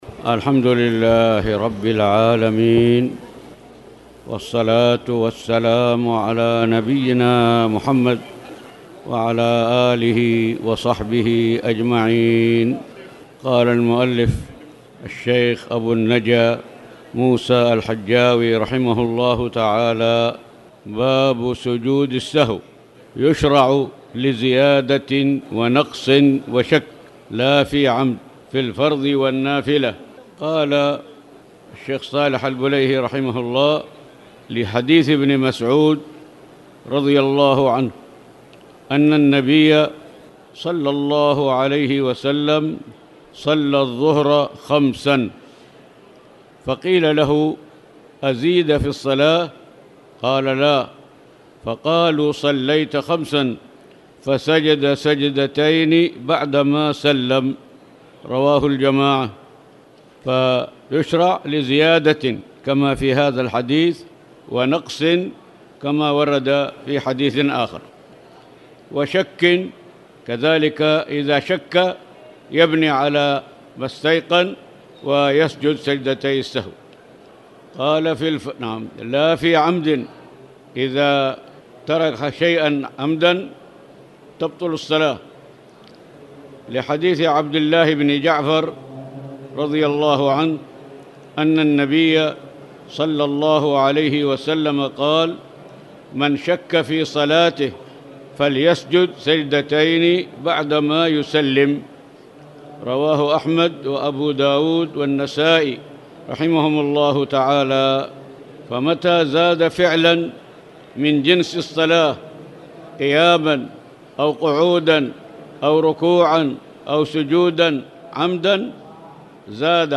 تاريخ النشر ٢٩ جمادى الآخرة ١٤٣٨ هـ المكان: المسجد الحرام الشيخ